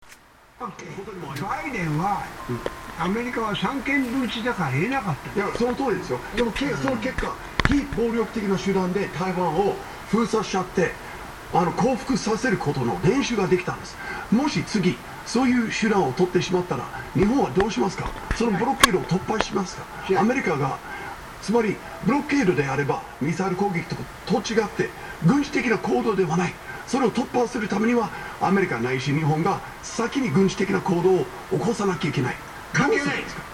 2023年03月25日放送分の朝まで生テレビの開始から2時間32分が経過した頃にパトリック・ハーラさんが次の様な発言をしている。